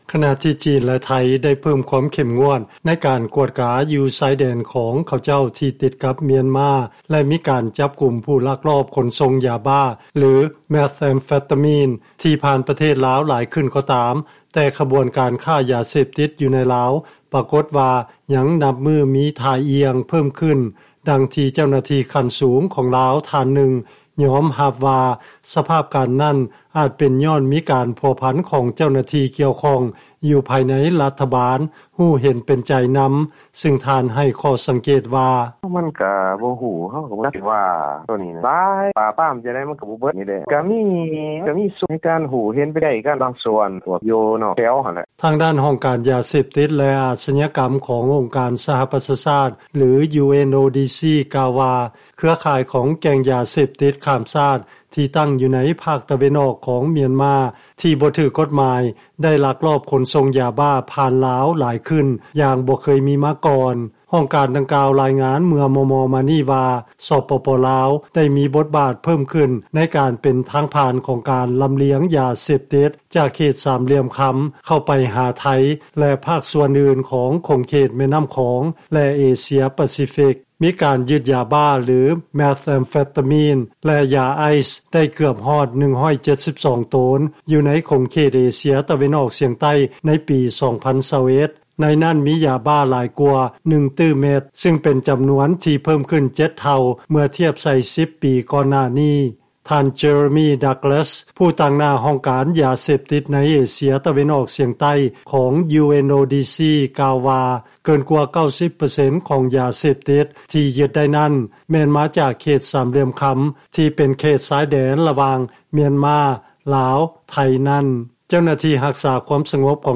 ຟັງລາຍງານ ບັນຫາຄວາມຍາກຈົນ ບວກໃສ່ກັບການປາບປາມຄ້າຢາເສບຕິດ ທີ່ມີປະສິດທິພາບຕ່ຳ ເປັນສາຍເຫດ ພາໃຫ້ຊາວໜຸ່ມລາວ ໄປພົວພັນກັບຢາເສບຕິດຫຼາຍຂຶ້ນ